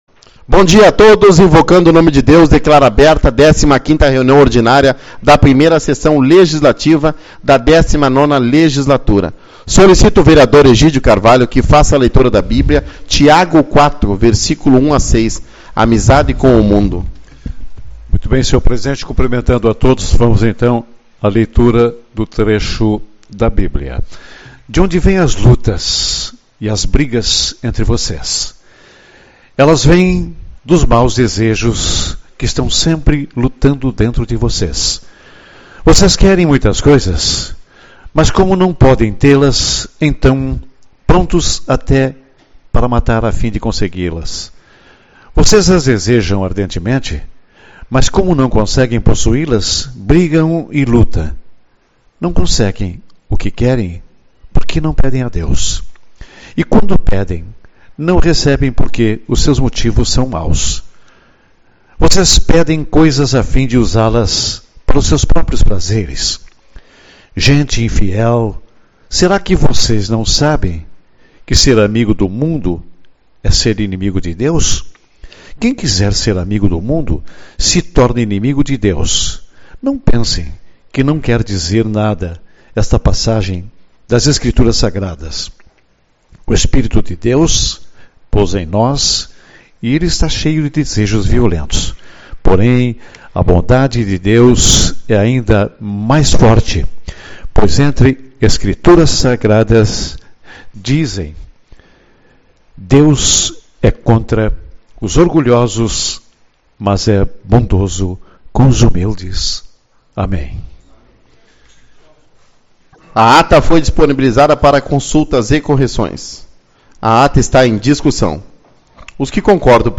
27/03 - Reunião Ordinária